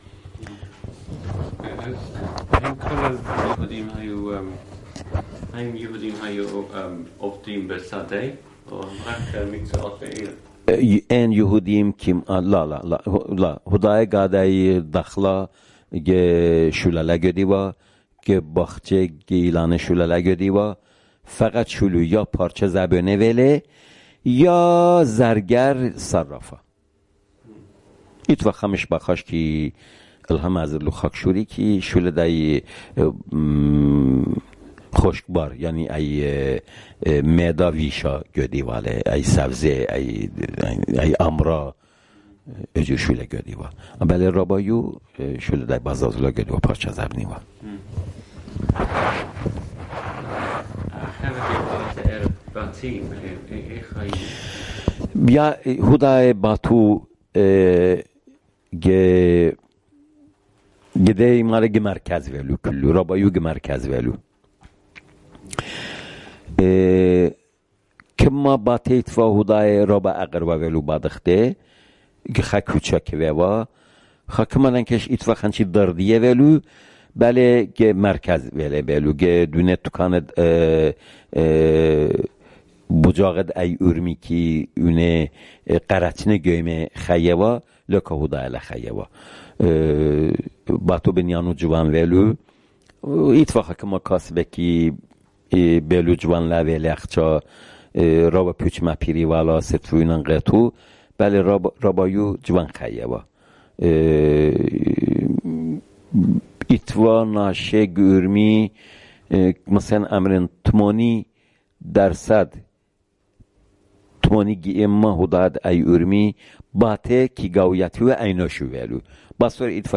Urmi, Jewish: The Jewish Quarter